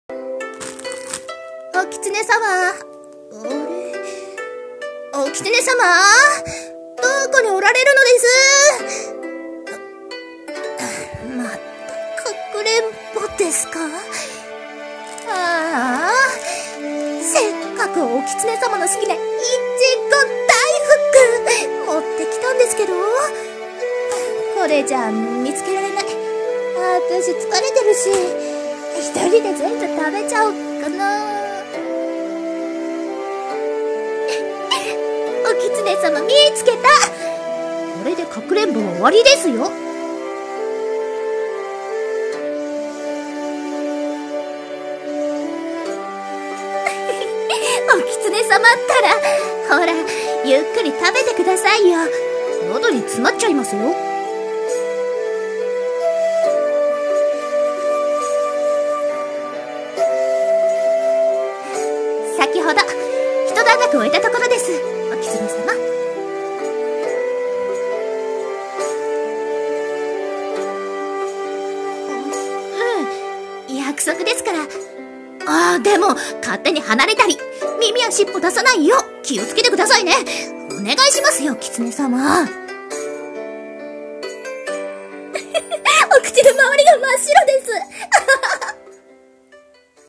【声劇】お狐様とお祭りでの約束事【掛け合い】